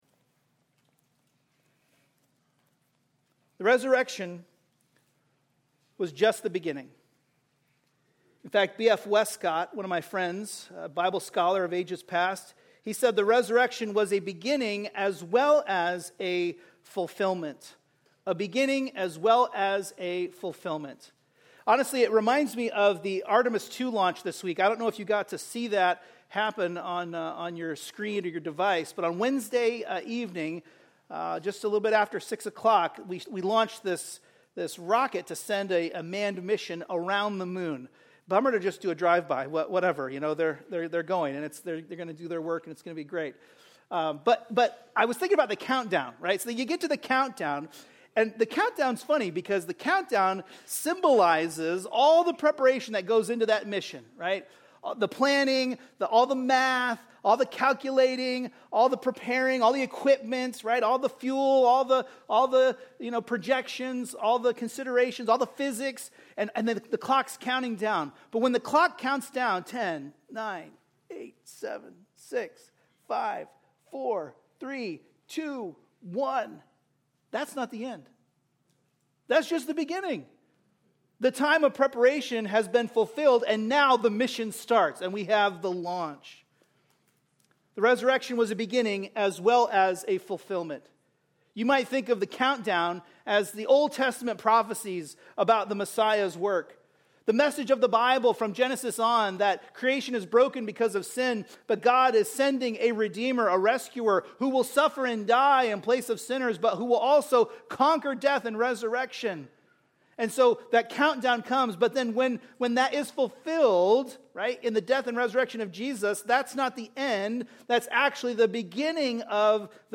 By Green Pond Bible Chapel Sermon